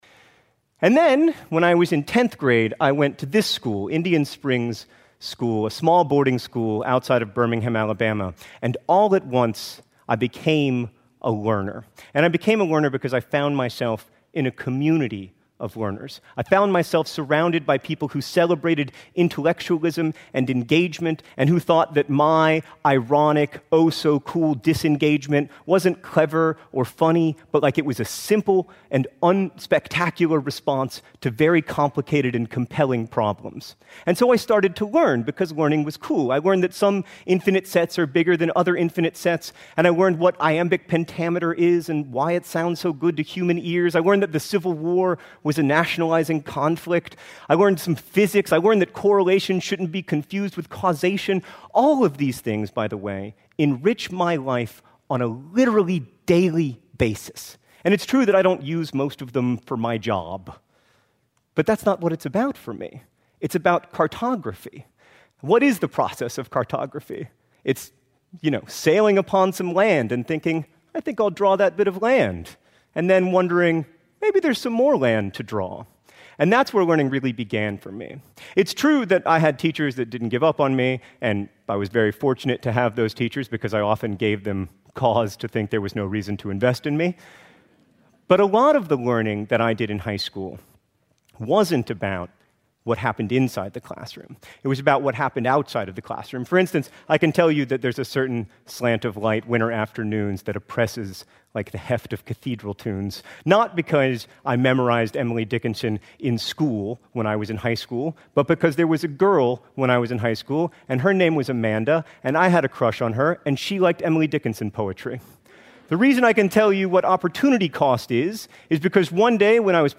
TED演讲:书呆子教你如何在线学习(4) 听力文件下载—在线英语听力室